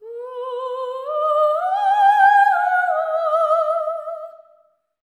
LEGATO 07 -L.wav